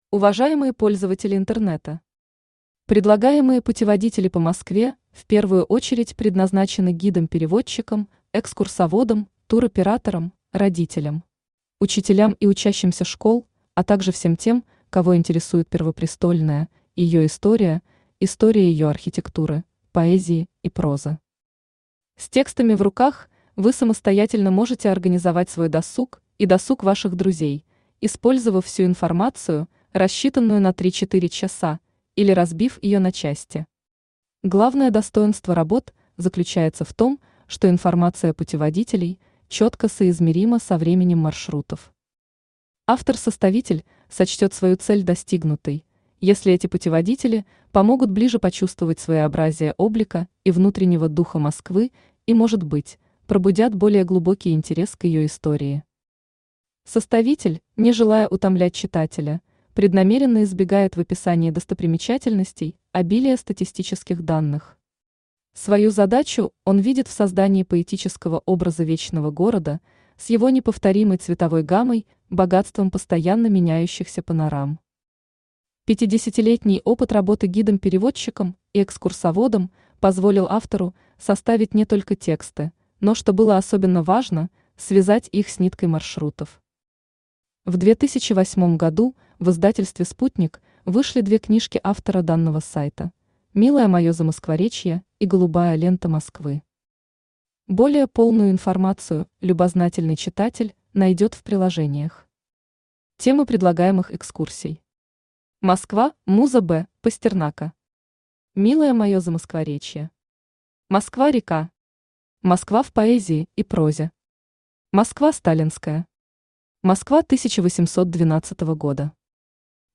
Аудиокнига Москва – муза Бориса Пастернака | Библиотека аудиокниг